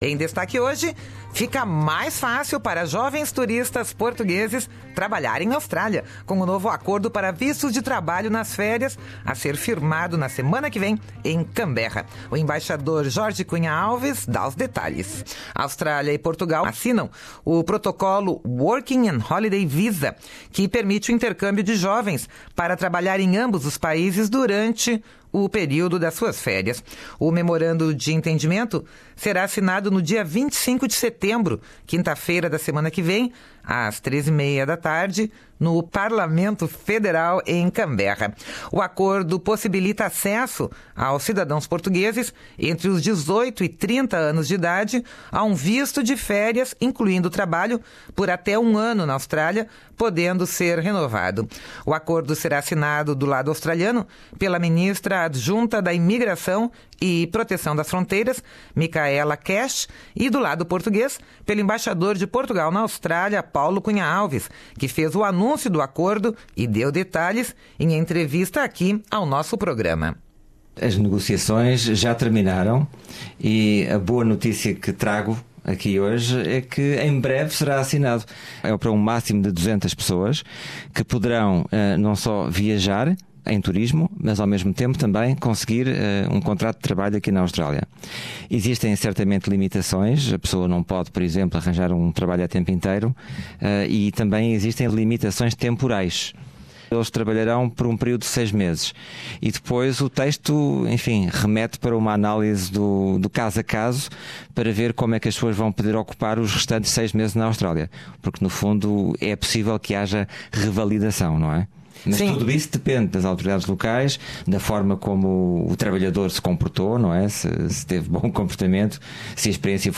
O acordo será assinado, do lado australiano, pela ministra adjunta da Imigração e Protecao das Fronteiras, Michaela Cash e, do lado português, pelo Embaixador de Portugal na Austrália, Paulo Cunha Alves, que fez o anúncio do acordo e deu detalhes, em entrevista ao nosso programa.